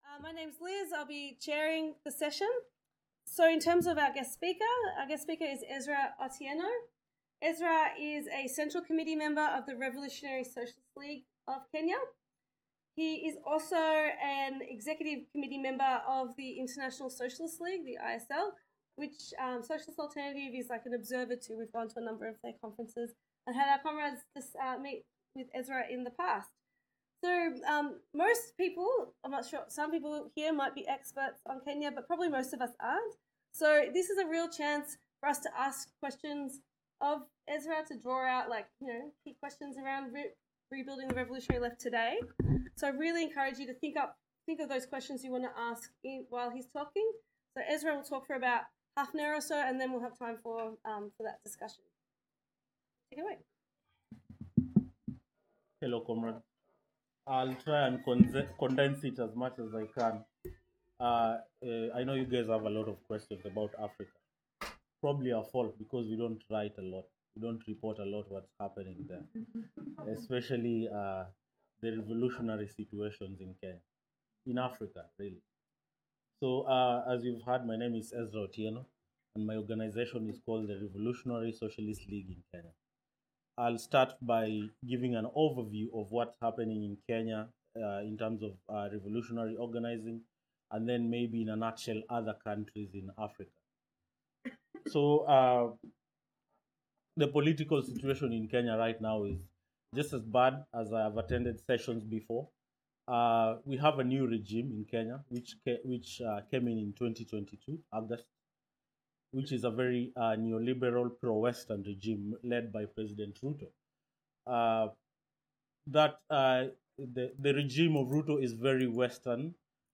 The Revolutionary Socialist League is a Marxist organisation, founded in Kenya in 2018 by radicals who rejected the pro-China politics of the Communist Party of Kenya. This session will feature RSL activists who will outline their struggle to build an internationalist and socialist left in Africa.